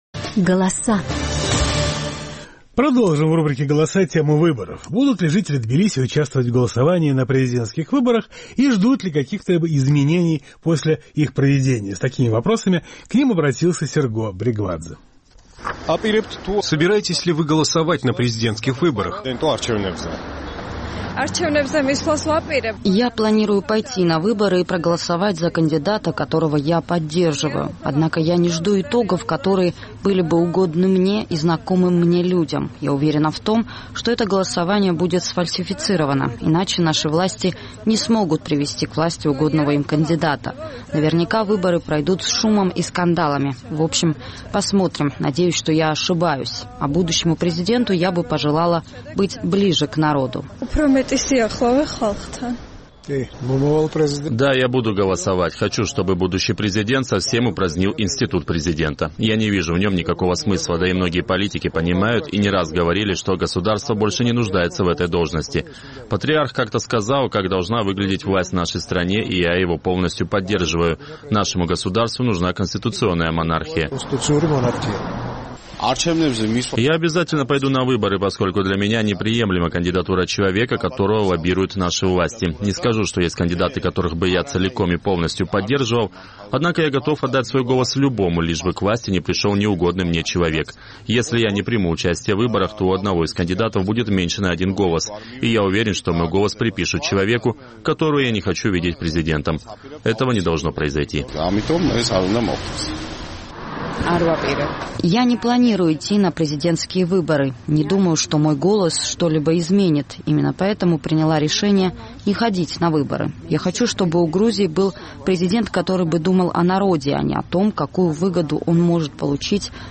Будут ли жители Тбилиси участвовать в голосовании на президентских выборах и ждут ли каких-либо изменений после их проведения? С такими вопросами к ним обратился наш тбилисский корреспондент.